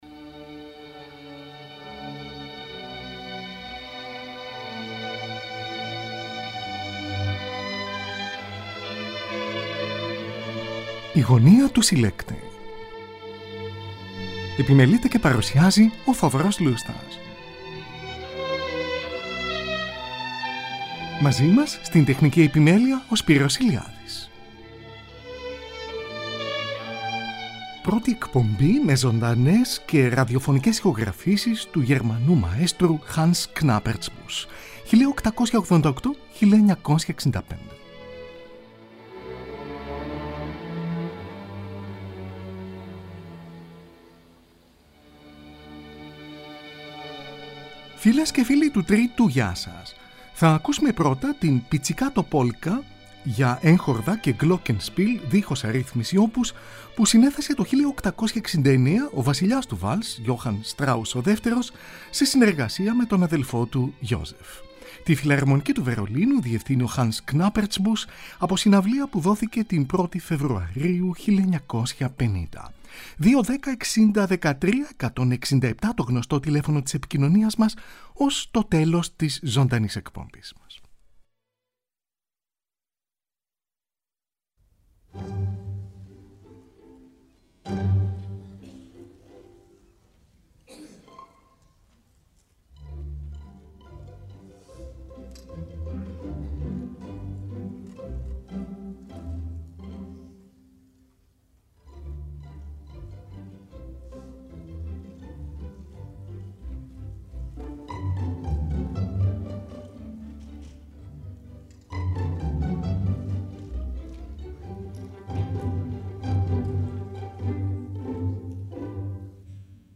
Πρώτη εκπομπή με ζωντανές και ραδιοφωνικές ηχογραφήσεις του Μαέστρου Hans Knappertsbusch (1888-1965)
Anton Bruckner: συμφωνία αρ.7, στην εκδοχή του 1885, από την έκδοση του Albert Gutmann.
Την Ορχήστρα της Ραδιοφωνίας της Κολωνίας διευθύνει ο Hans Knappertsbusch, από ζωντανή ηχογράφηση στις 10.5.1963.